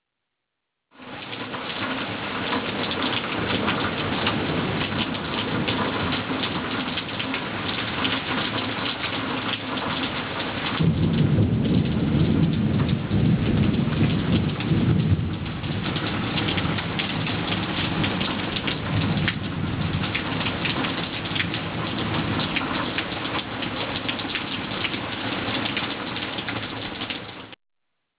rain_forest.au